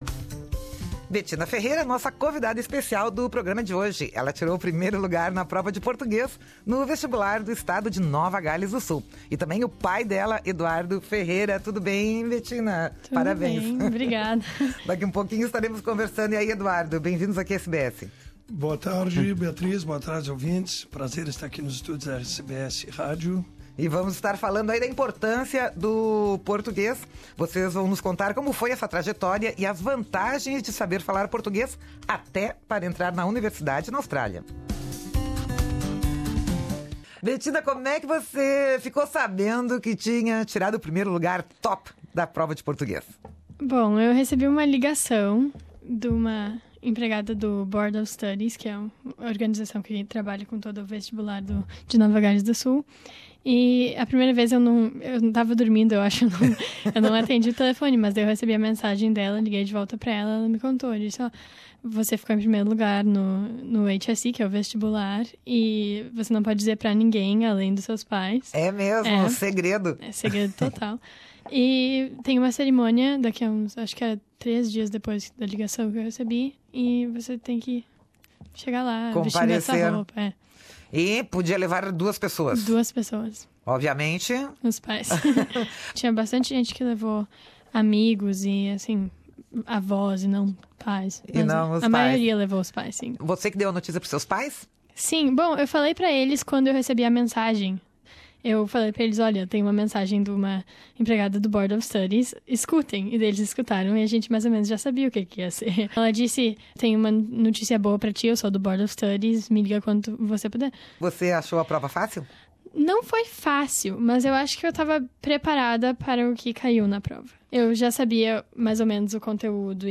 ela é a convidada especial do programa de hoje da Rádio SBS